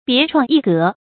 别创一格 bié chuàng yí gé
别创一格发音